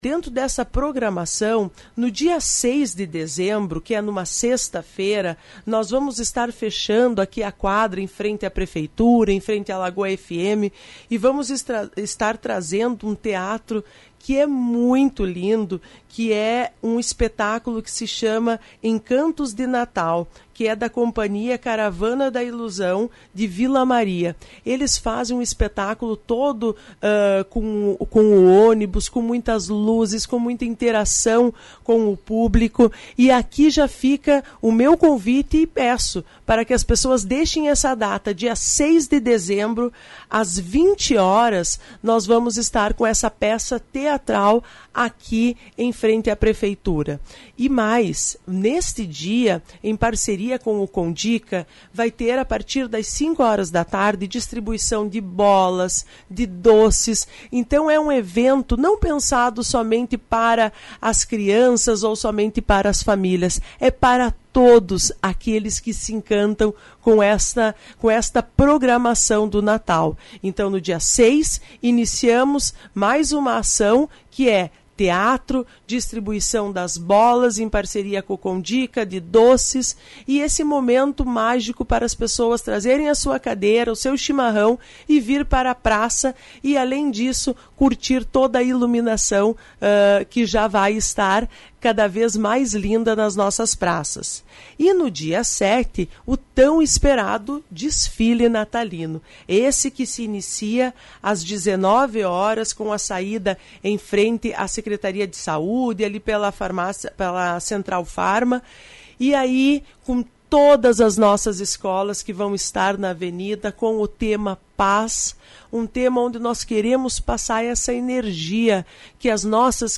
Secretária da Educação, Aline Teles da Silva dá mais detalhes.